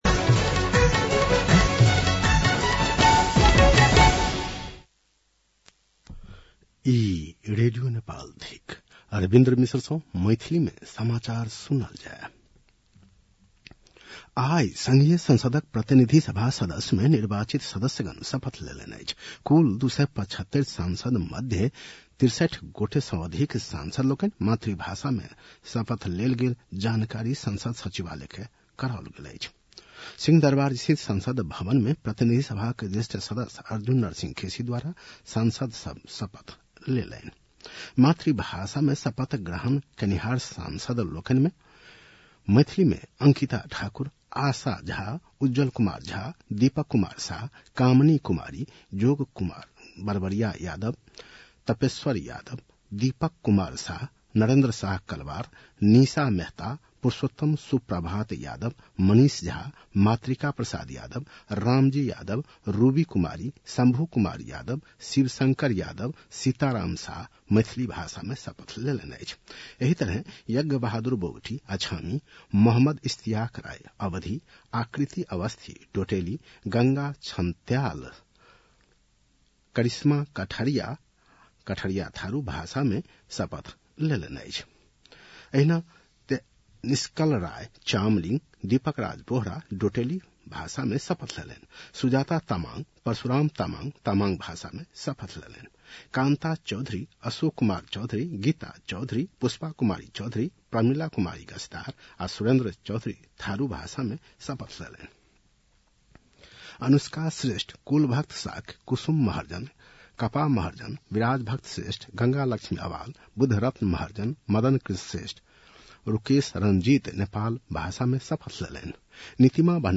मैथिली भाषामा समाचार : १२ चैत , २०८२
6.-pm-maithali-news-1-4.mp3